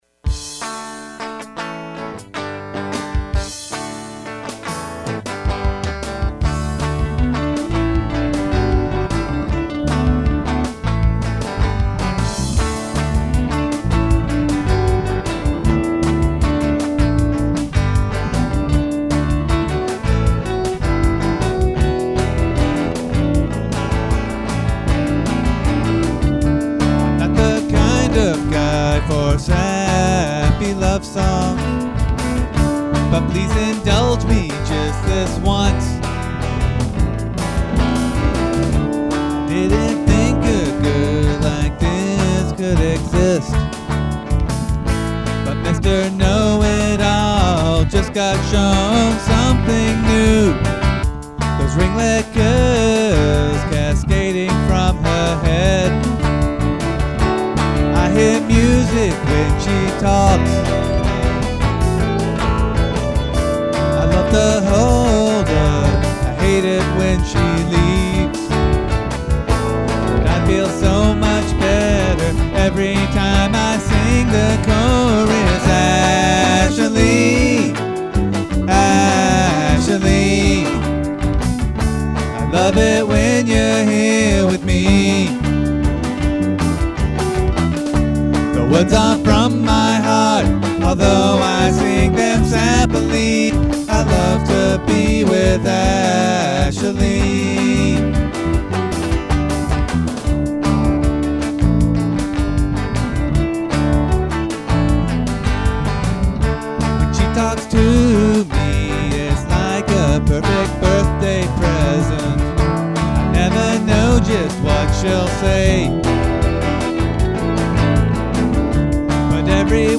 Unless otherwise noted, they're all originals, and I'm playing everything and singing.
The drums I've programmed as quickly as possible, but it's mostly a groove quantize over a couple of bass patterns.